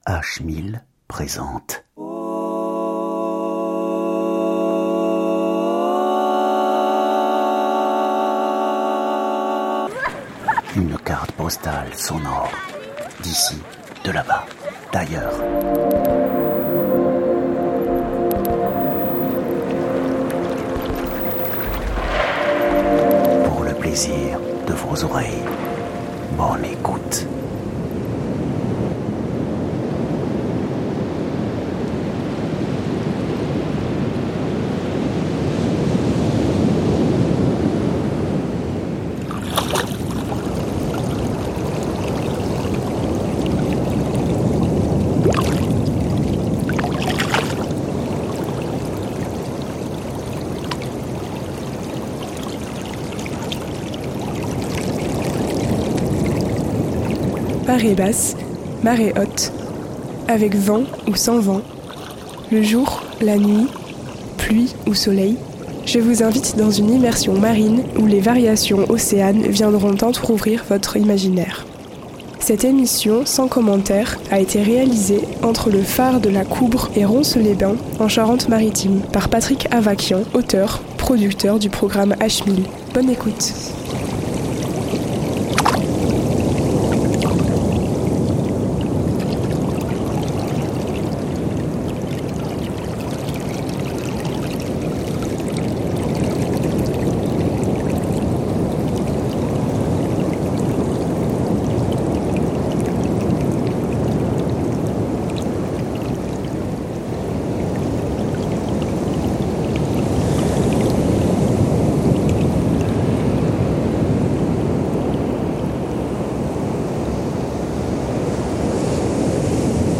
Cette semaine dans H1000, découvrez une carte postale auditive d'une marche océane!